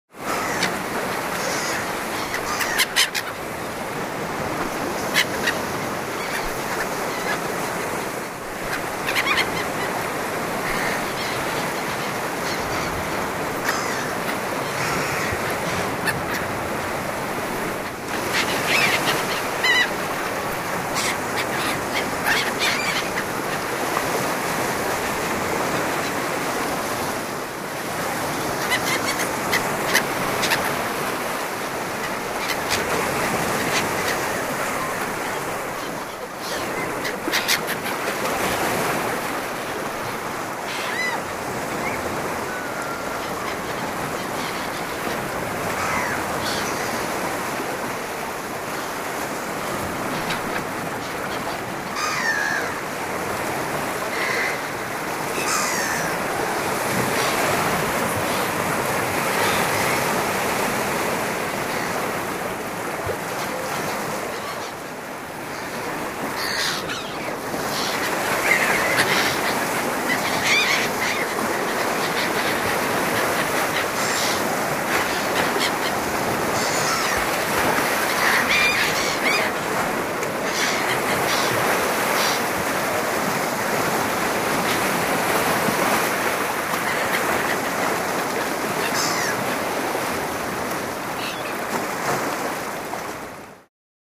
Звуки моря, океана
Шум прибоя и крики чаек